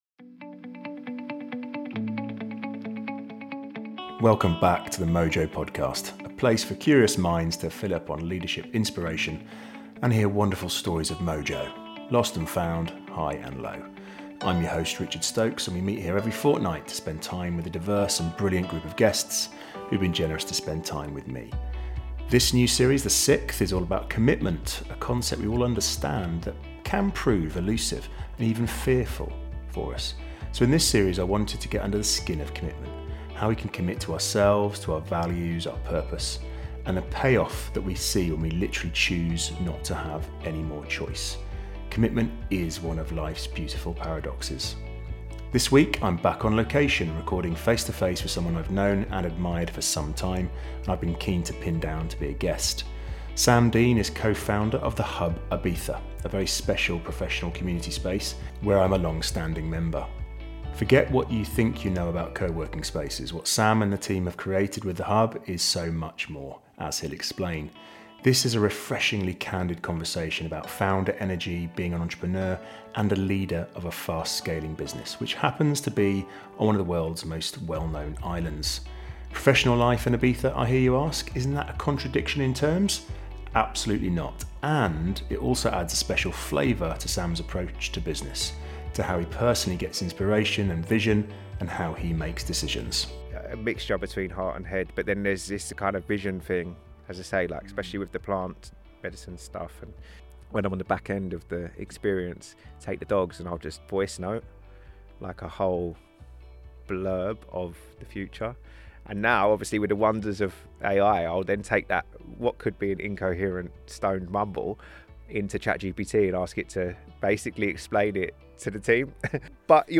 This is a refreshingly candid conversation about founder energy, being an entrepreneur and a leader of a fast scaling business - which happens to be in one of the most well-known islands in the world.